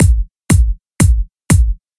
Now we have our samples loaded in, I’ve layed them out on a track so they sit on every beat like this:
This is how they sound all together: